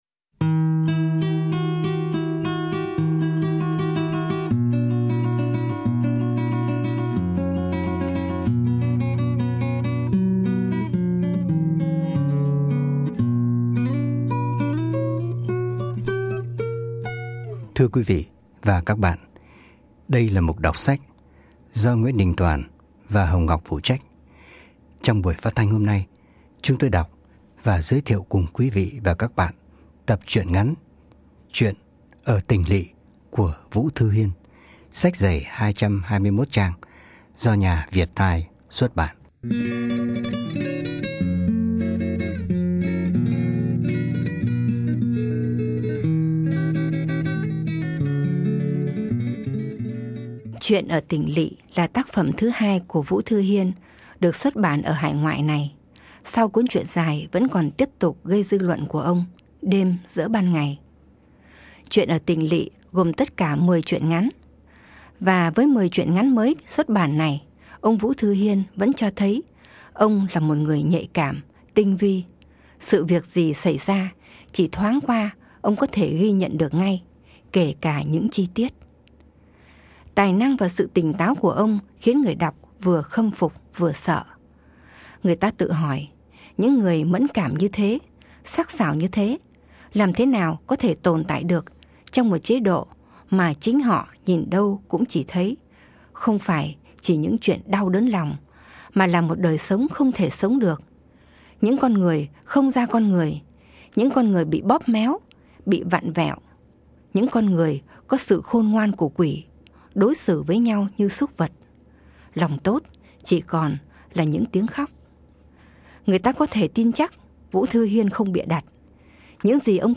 Ở cái giới hạn của thời lượng phát thanh, giọng của người đọc trở thành một yếu tố quan trọng trong việc chuyển tải nội dung.